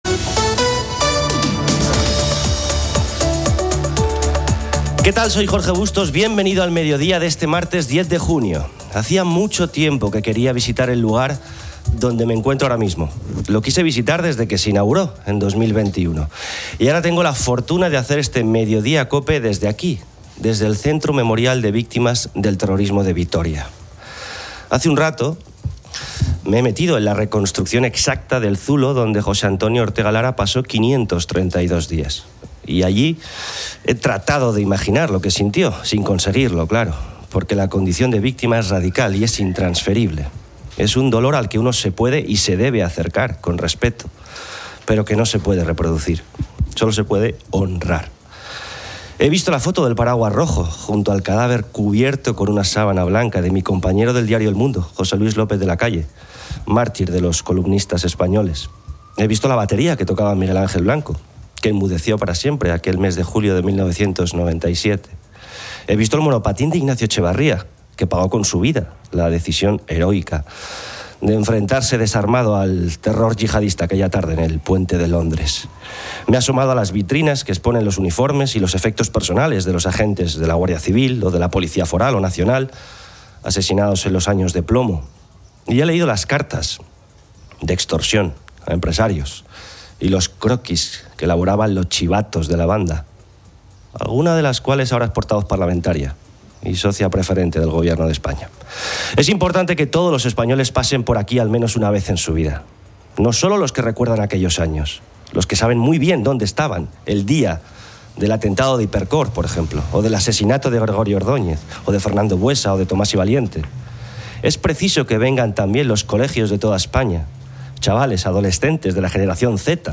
Y ahora tengo la fortuna de hacer este mediodía cope desde aquí, desde el Centro Memorial de Víctimas del Terrorismo de Vitoria.